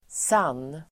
Uttal: [san:]